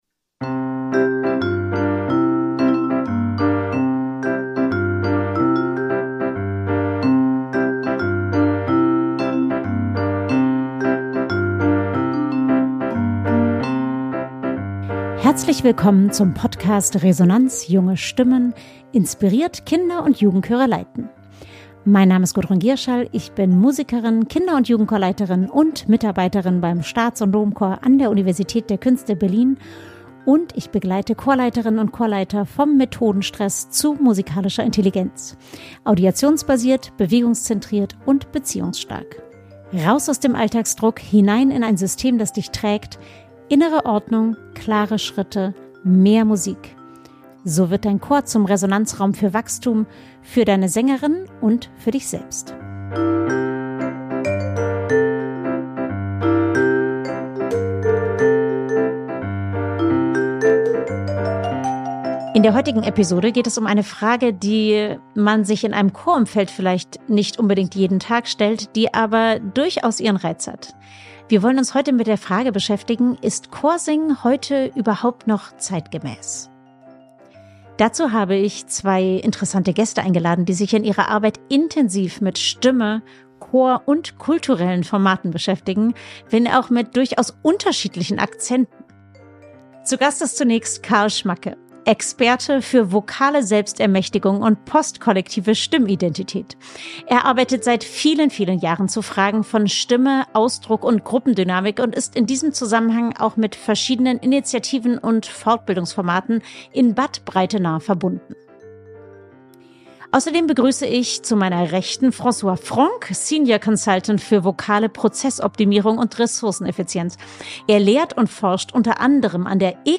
In dieser besonderen Folge zum ersten April diskutieren zwei höchst eigensinnige Spezialgäste über Effizienz, Individualismus, Selbstverwirklichung und die Zukunft musikalischer Gemeinschaft.